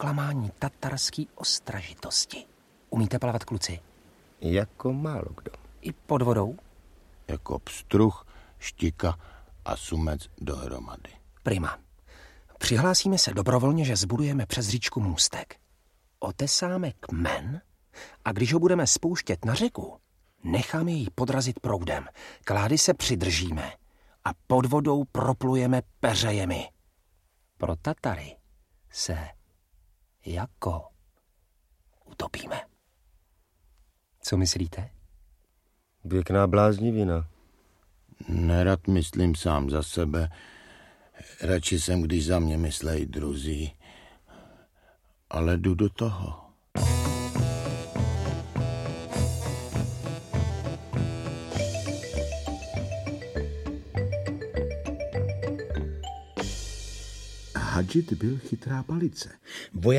Audiobook
Read: Lubomír Lipský